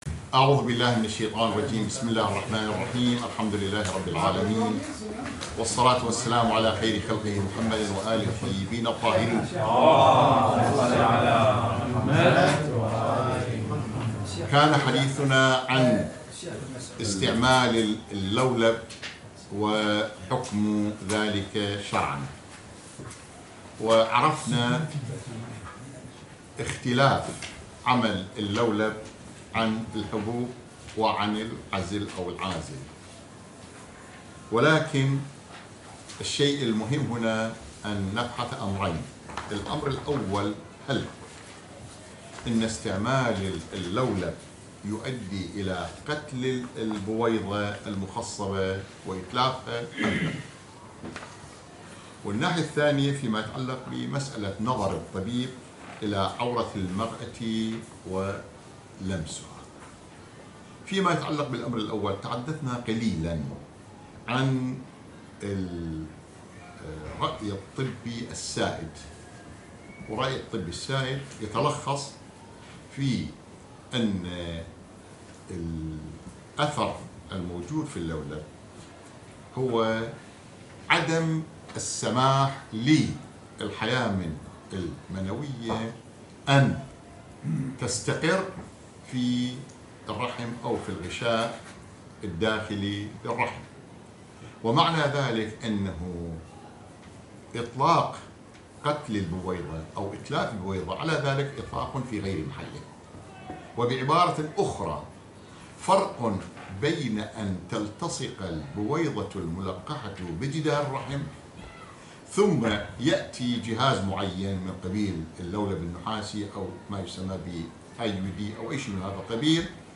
تنظيم النسل 10 دروس الفقه المحاضرة العاشرة